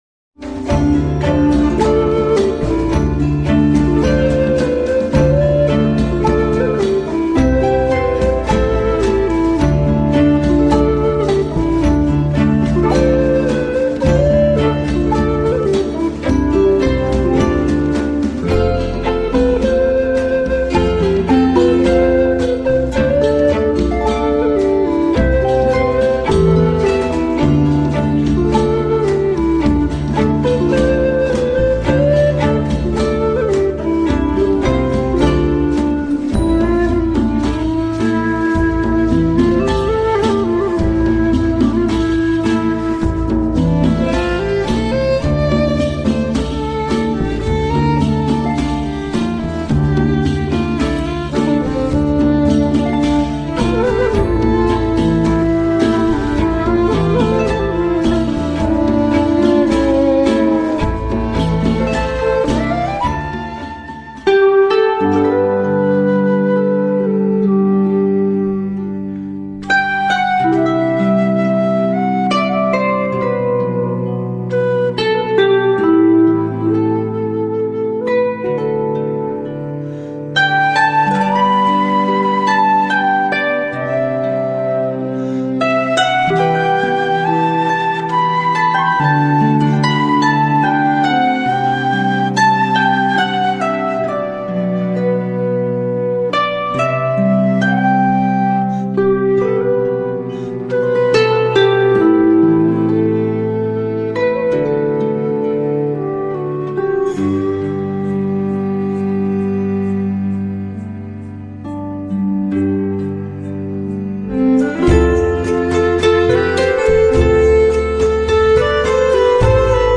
It is played very softly by rubbing hands across the skin.